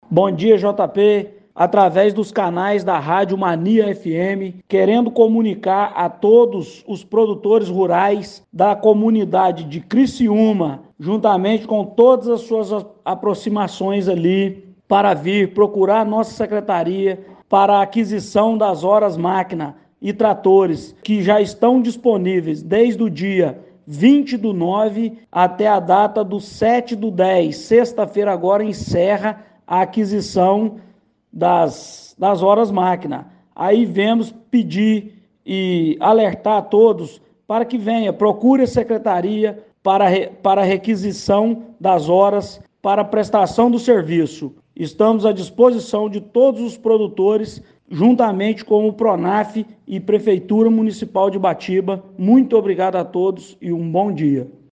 O secretário Municipal de Agricultura, Indústria e Comércio Eduardo José da Silveira conversou com nossa reportagem.